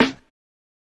(Snare) - Dilla.wav